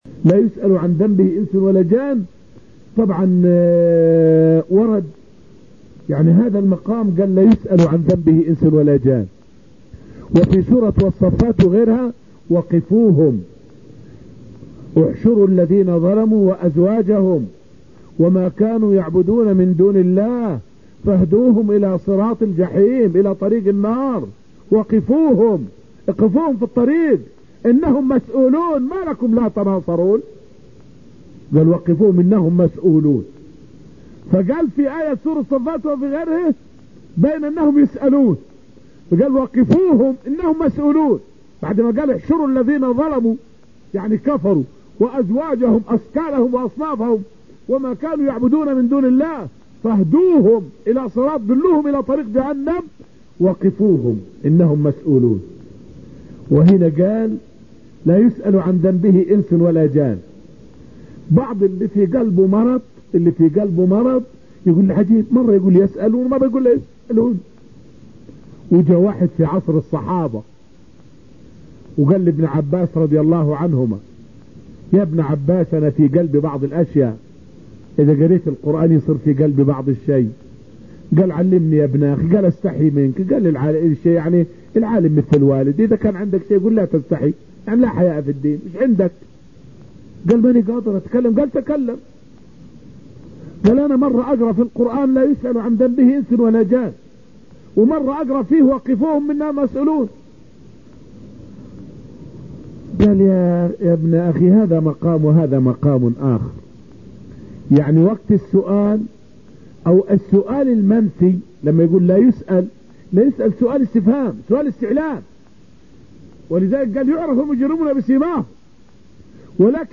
فائدة من الدرس السادس من دروس تفسير سورة الرحمن والتي ألقيت في المسجد النبوي الشريف حول الجمع بين قوله: {رب المشرق والمغرب} {رب المشرقين ورب المغربين} {رب المشارق والمغارب}.